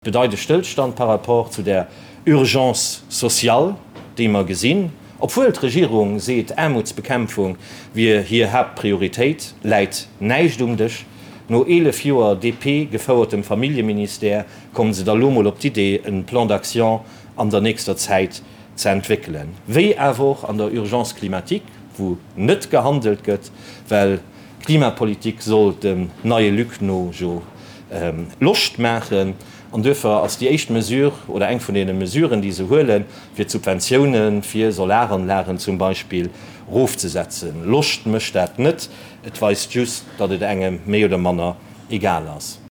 Déi nei Regierung bedreift eng Politik vun der Verantwortungslosegkeet. Dee Bilan zéien Déi Lénk op hirem traditionelle Presseiessen um Enn vum Chamberjoer.